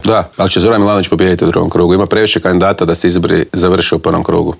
"Na idućim parlamentarim izborima SDP će biti prvi, kandidirao sam se za predsjednika stranke da budem premijer", otkrio nam je u Intervjuu tjedna Media servisa predsjednik najjače oporbene stranke Siniša Hajdaš Dončić.